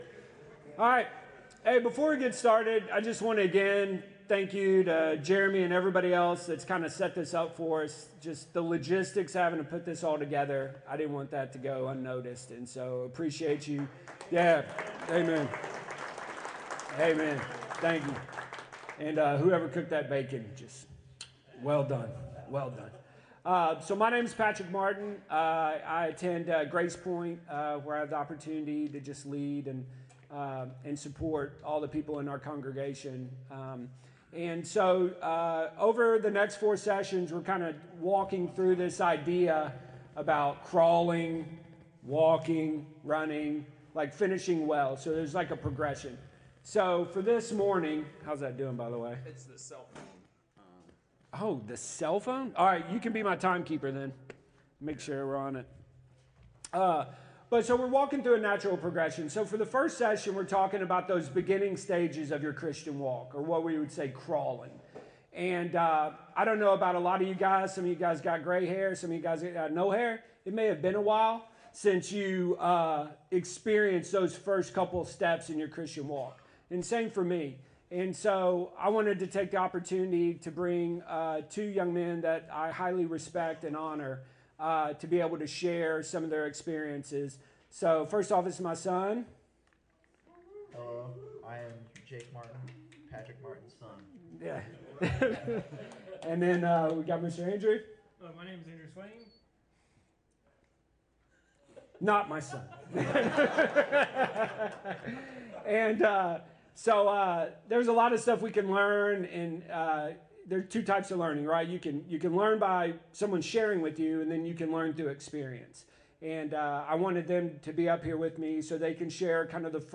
Session 1 of the Faithful Masculinity men’s retreat